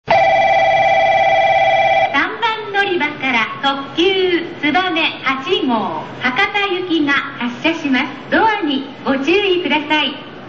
３番のりば 発車放送 特急つばめ・博多 (51KB/10秒)
九州標準放送です。